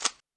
m3_close.wav